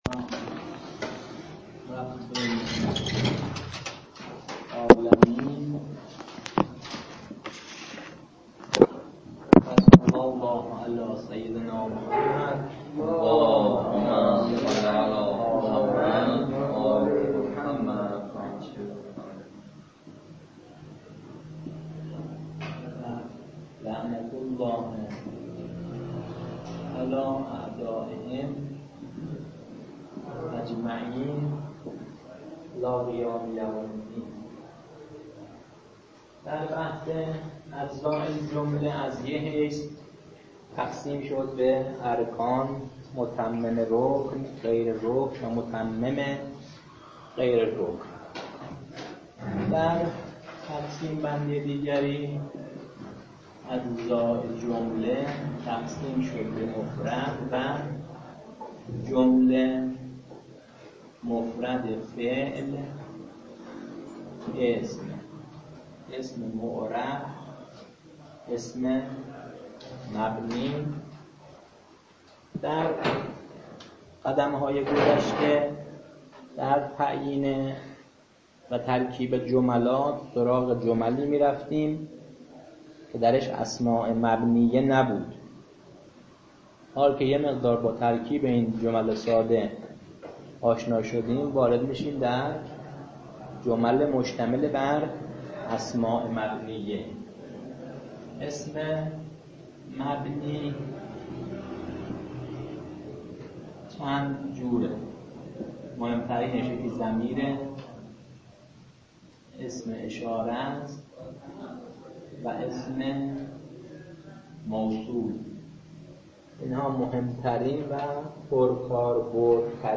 آموزش ترکیب